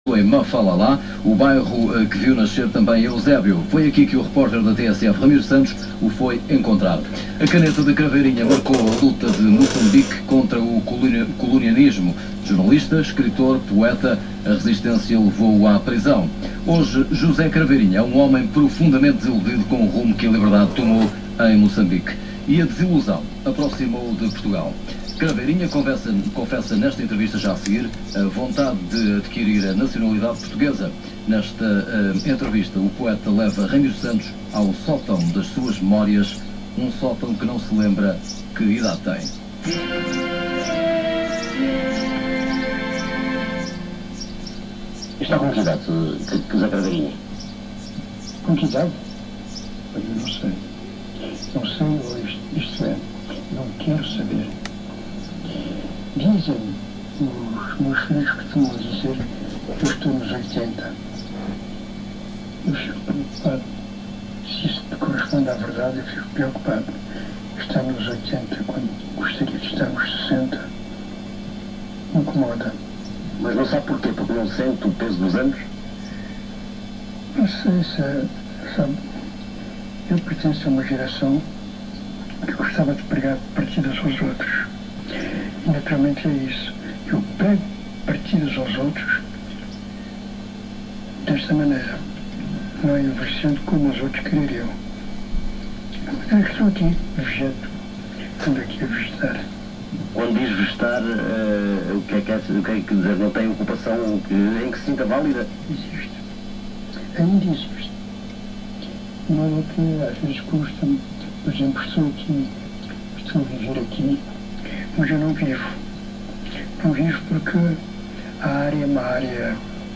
Entrevista concedida à TSF, de Lisboa, meses antes de completar 80 anos, em 28 de Maio de 2002.